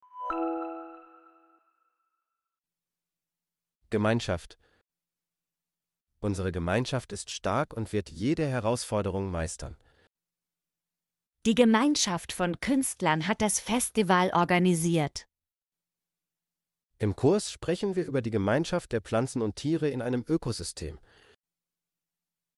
gemeinschaft - Example Sentences & Pronunciation, German Frequency List